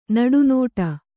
♪ naḍu nōṭa